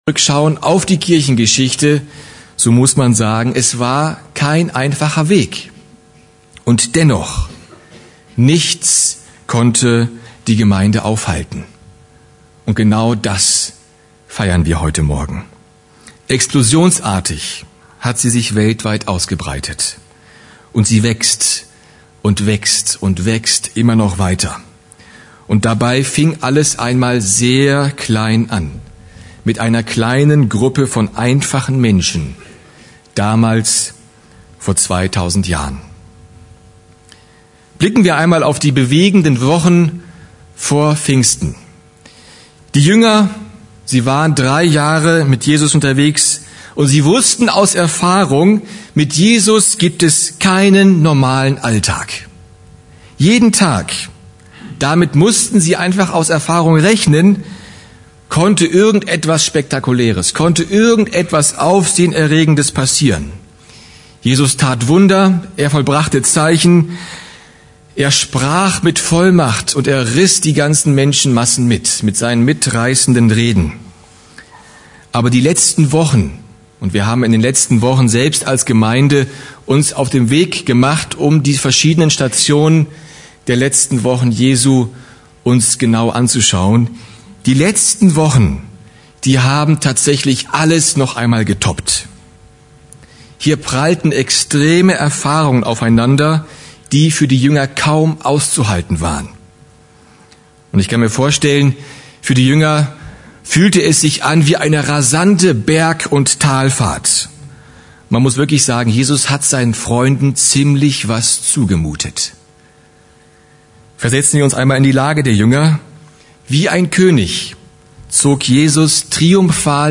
Serie: Einzelpredigten